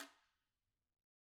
Guiro-Hit_v1_rr2_Sum.wav